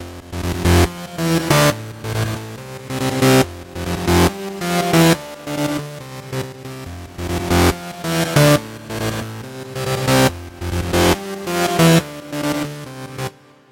雨声合成器侧链
描述：在Fmaj中以140 bpm的速度为雨点合成器，为雨点节拍进行侧链。
Tag: 140 bpm Dubstep Loops Synth Loops 2.31 MB wav Key : F